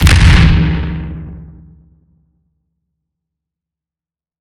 Drama Boom
bang boom boomer comedy comic Drama fx industrial sound effect free sound royalty free Memes